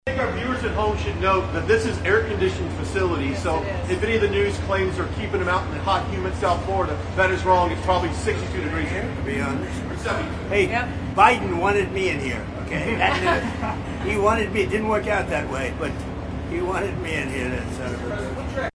Trump also made a funny comment while he was touring the facility. He stated that Biden wanted to put him in prison there.